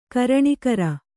♪ karaṇikara